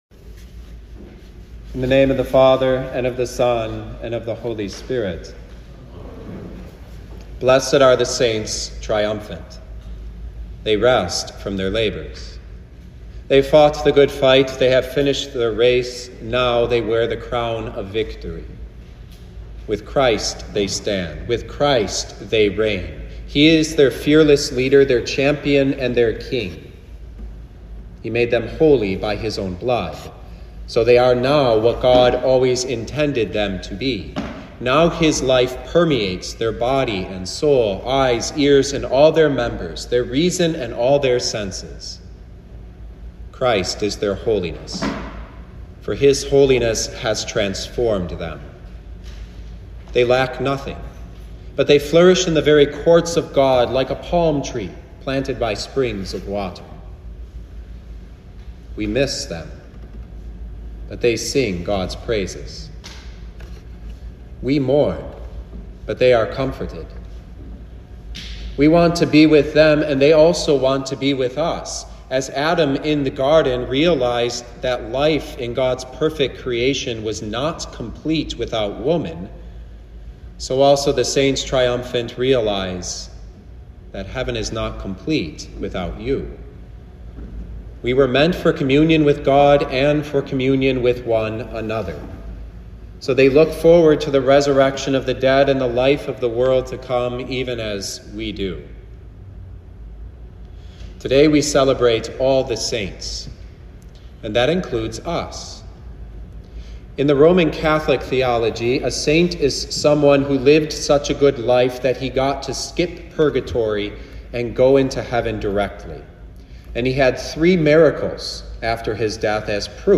2024 Feast of All Saints Preacher